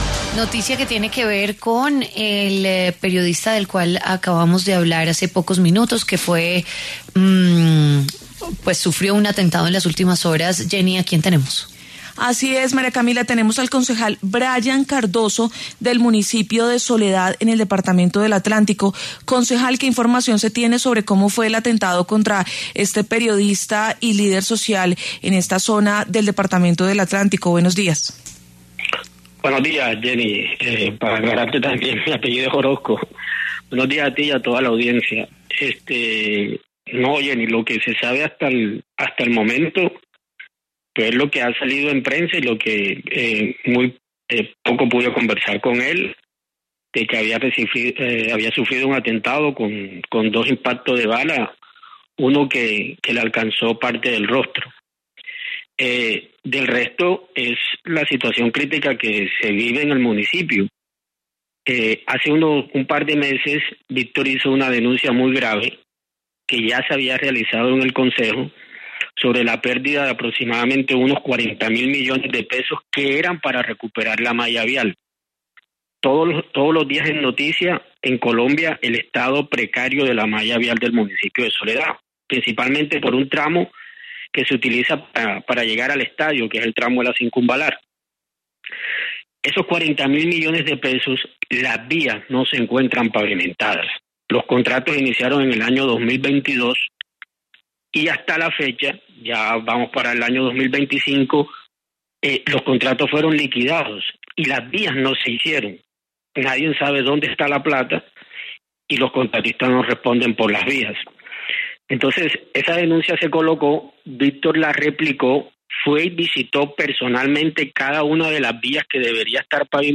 Frente a esto, Brayan Orozco, concejal de Soledad, Atlántico, pasó por los micrófonos de W Fin de Semana, y comentó que el líder recibió dos impactos de bala “uno que le alcanzó parte del rostro”.